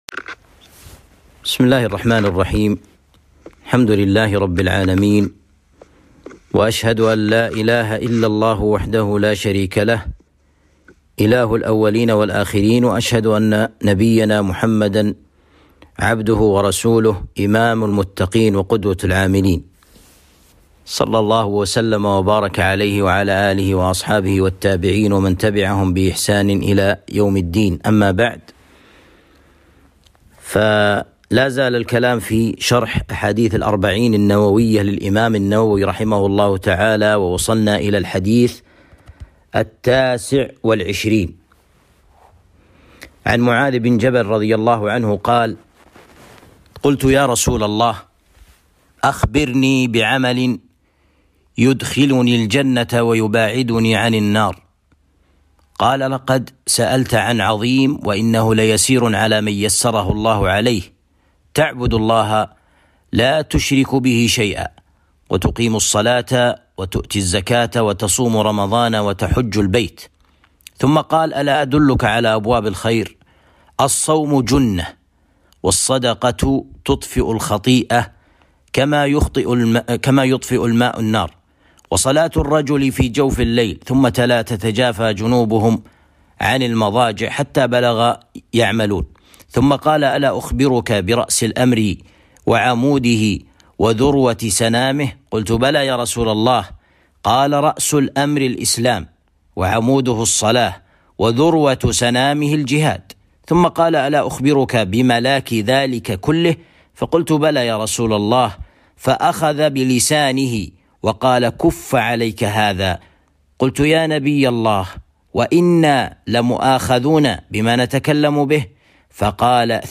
الدروس شرح الأربعين النووية